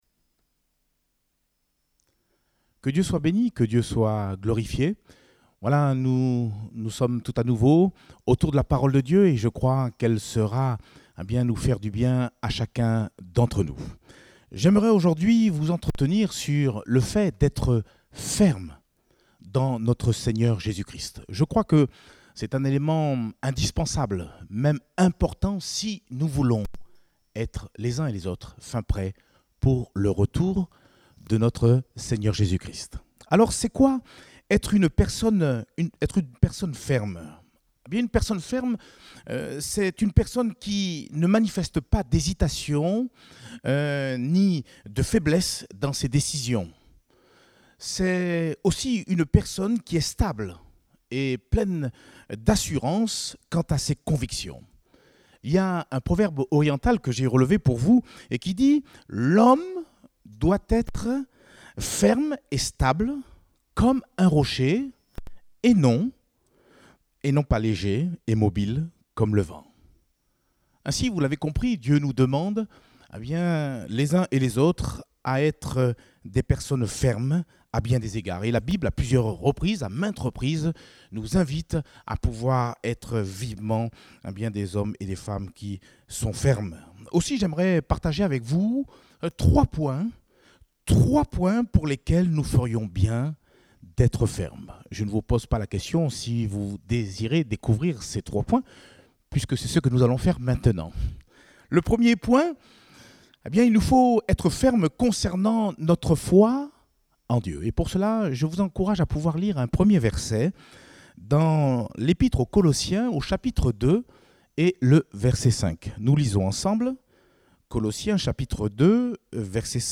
Type De Service: Culte Dominical